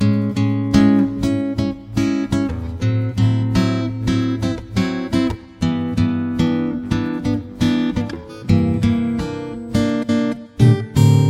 原声吉他循环2
Tag: 85 bpm Acoustic Loops Guitar Acoustic Loops 1.90 MB wav Key : D